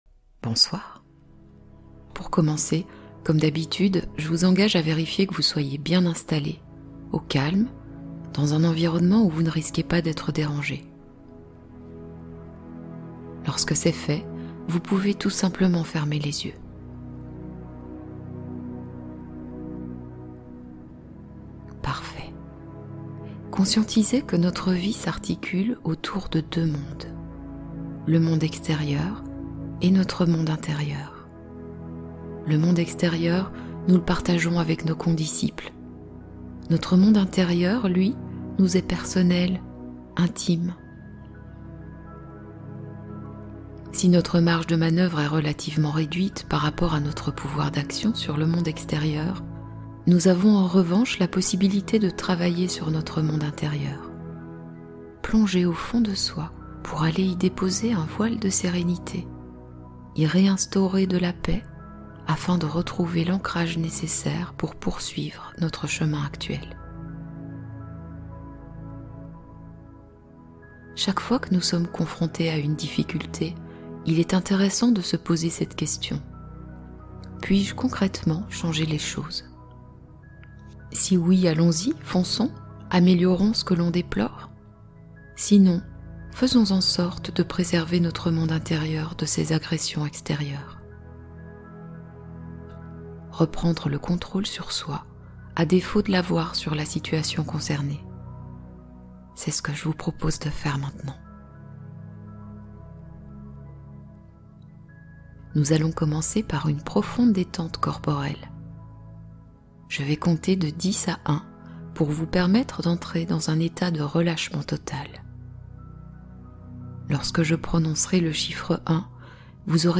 Accompagner les insomnies : hypnose réparatrice du soir